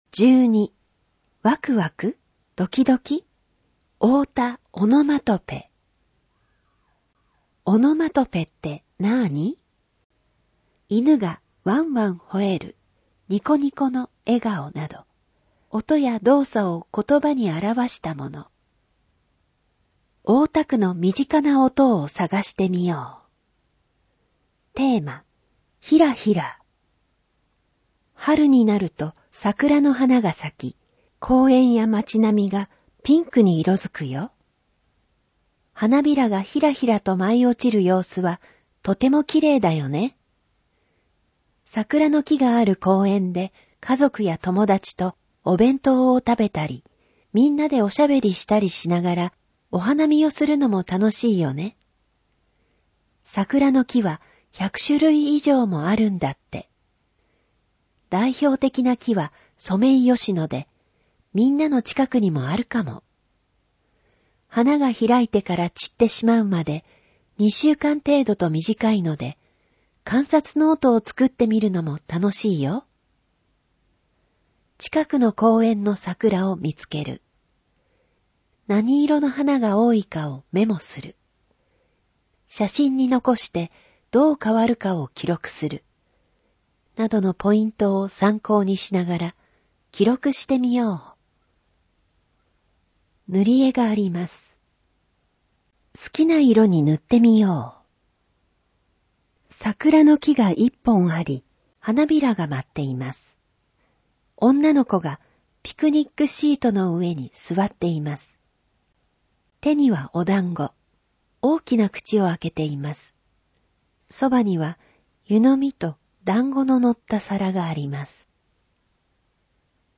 なお、この音声版は、障がい者総合サポートセンター声の図書室で製作したCDを再生したものです。